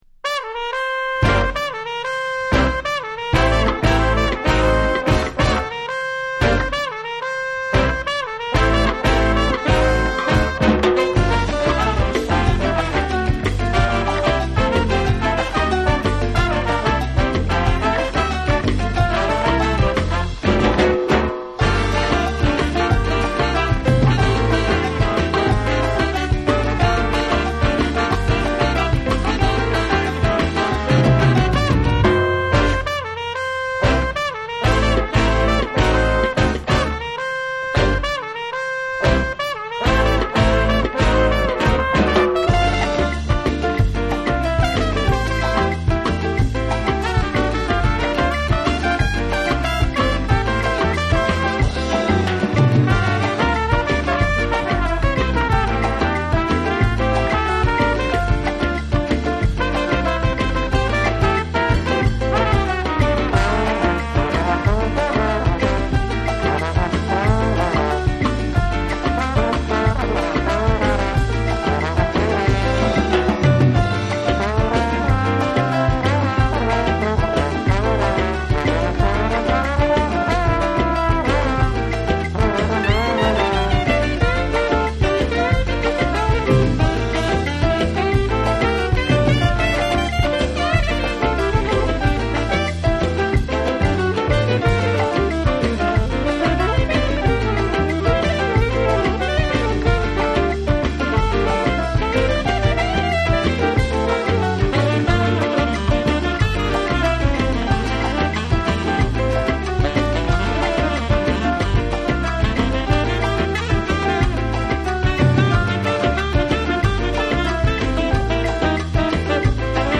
JAPANESE / REGGAE & DUB